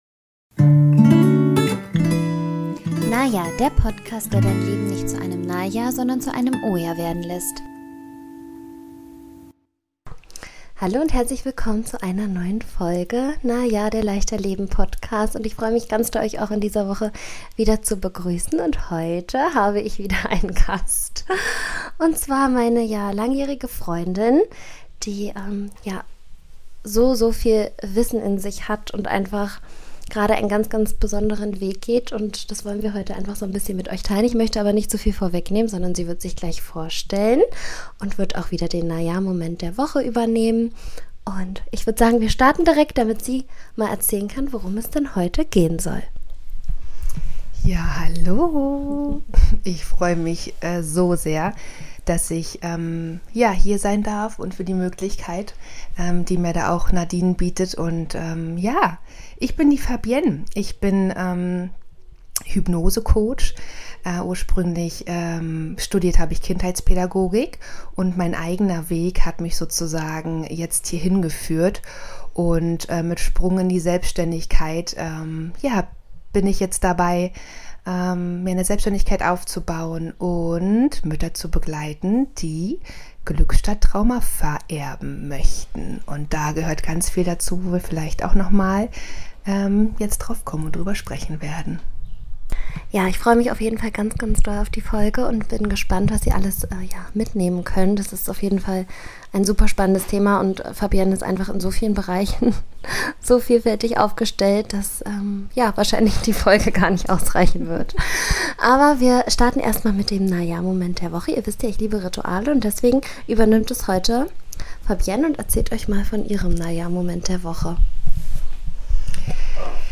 In der heutigen Folge habe ich einen Gast.